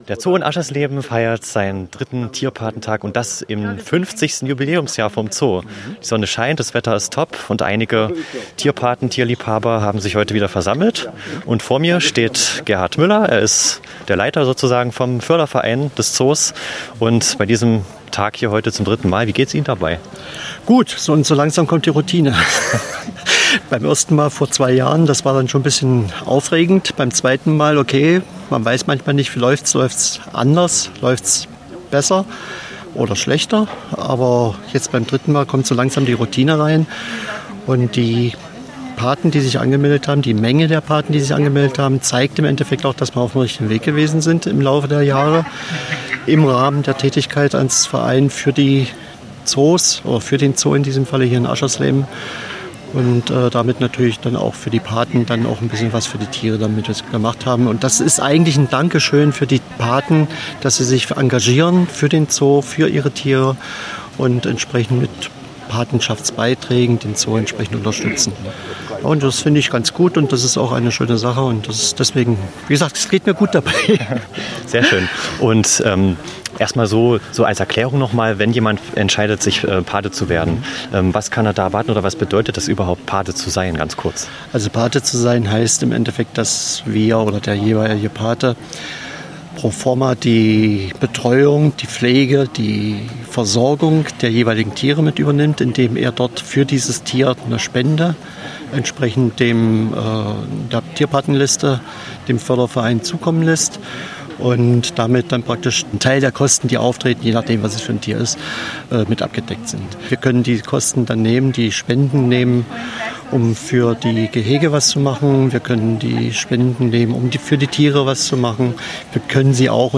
Hörbeitrag vom 20. August 2023